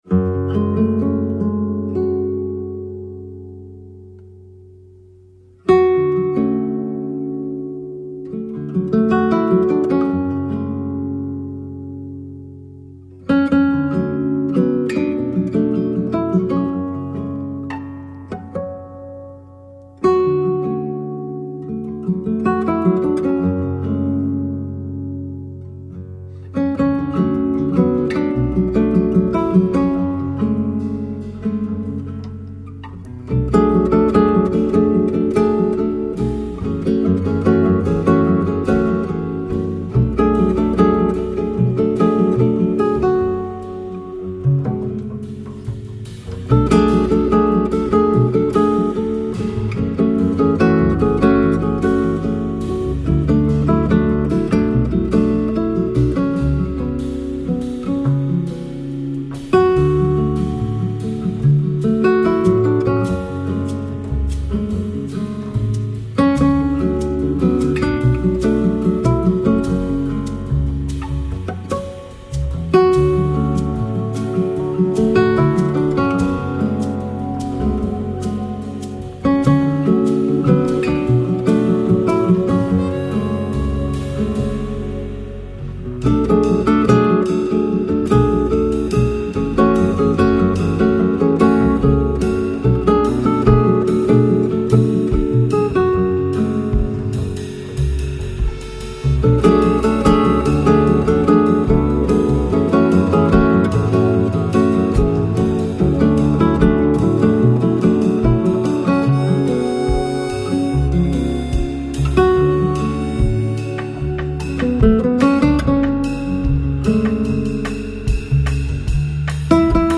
Downtempo, Chill Out Год издания